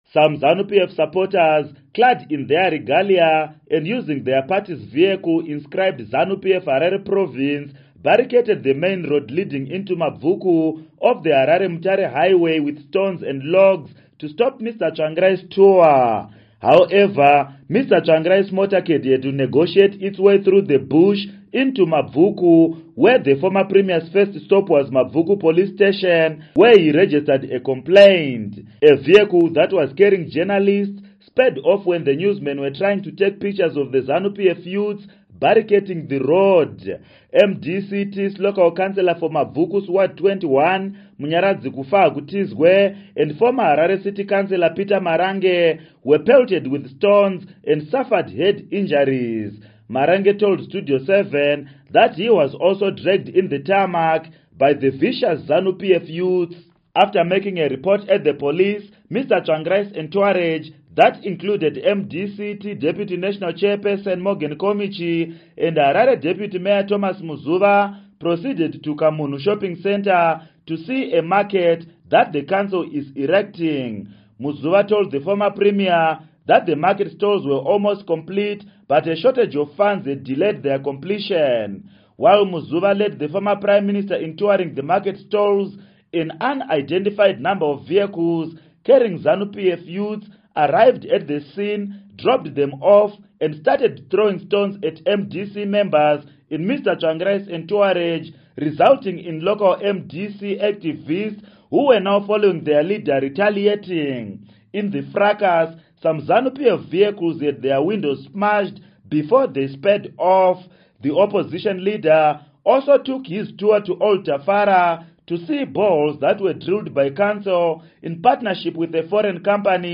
Report on Zanu PF, MDC-T Youth Clashes